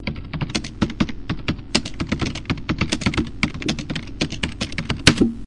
磁带点击的声音
描述：一个磁带点击的声音。
Tag: 闷响 点击 磁带